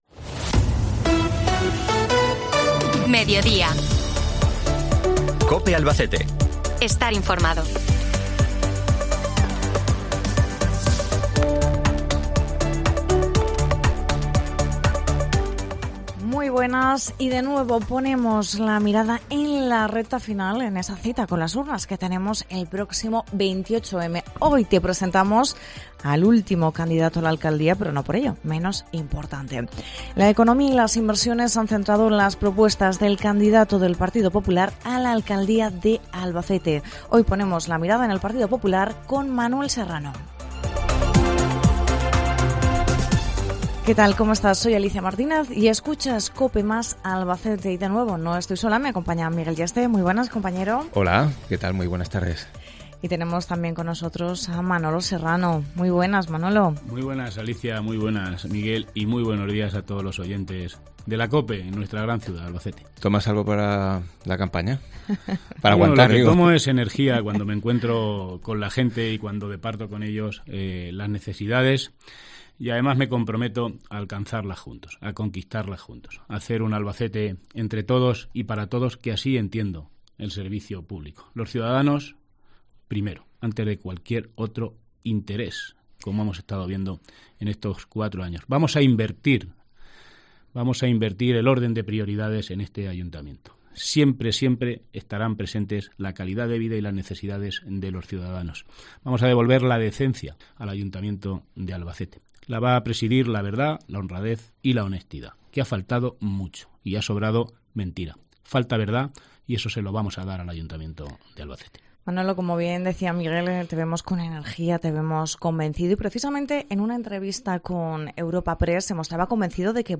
Con Manuel Serrano, Cope Albacete completa la ronda de entrevistas a los candidatos que optan a la alcaldía el próximo 28 de mayo en la ciudad de Albacete.
Entrevista Manolo Serrano, candidato a la alcaldía de Albacete por el Partido Popular